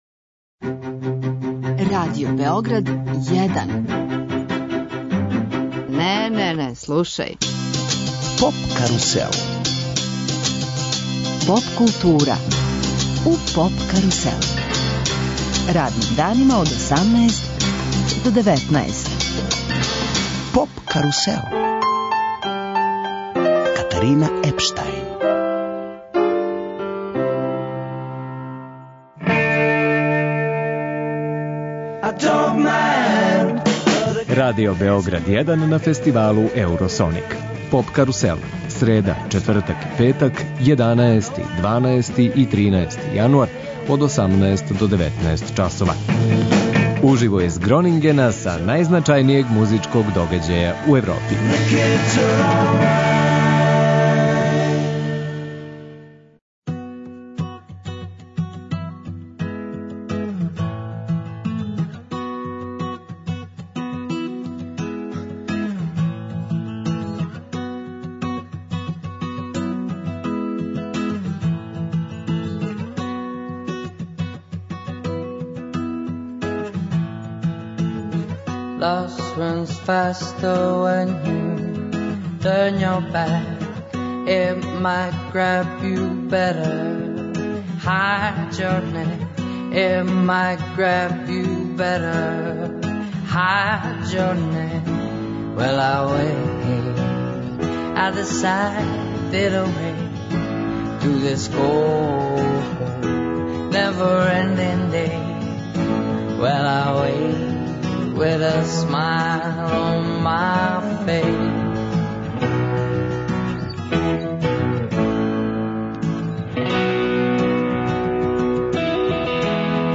Радио Београд 1 ексклузивно емитује емисије уживо са водећег европског музичког фестивала Еуросоник.